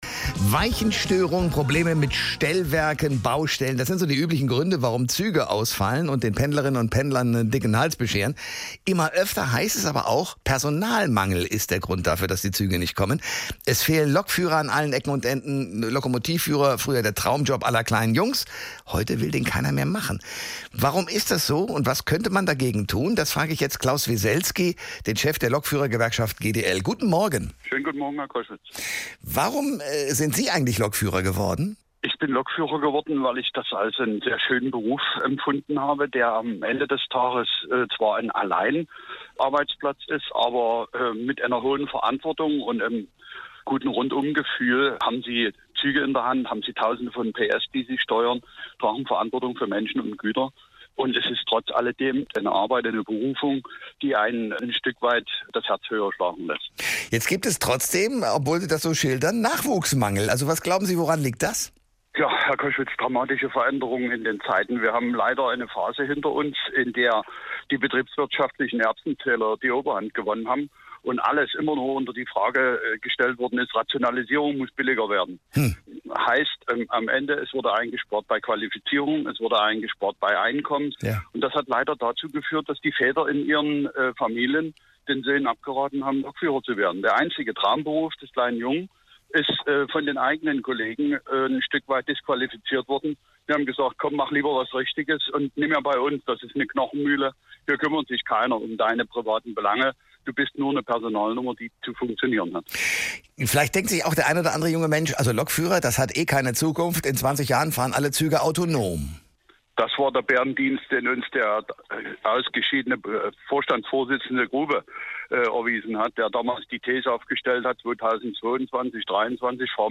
Im Gespräch mit Moderator Thomas Koschwitz benennt der GDL-Bundesvorsitzende Claus Weselsky auf hr1 die Ursachen des Lokführermangels und räumt mit der Mär vom baldigen autonomen Fahren auf der Schiene auf.